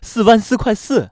surprise